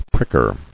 [prick·er]